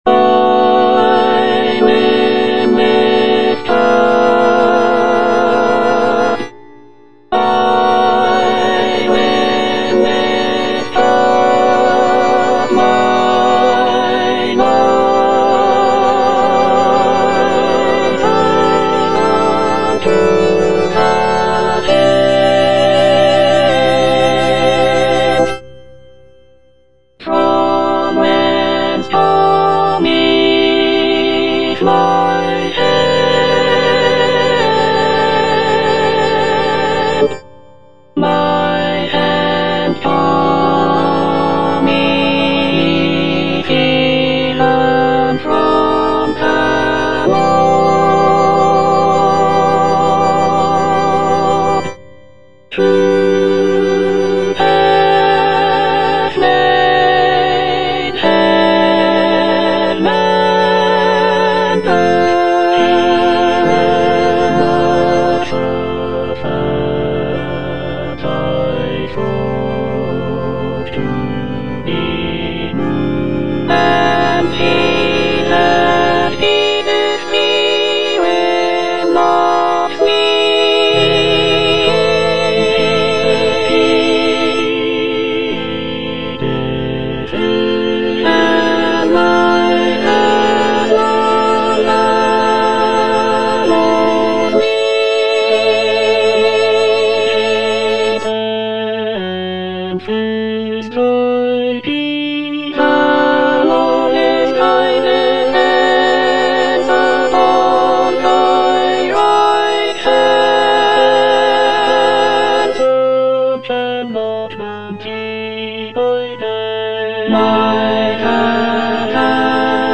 Alto I (Emphasised voice and other voices)
is a choral work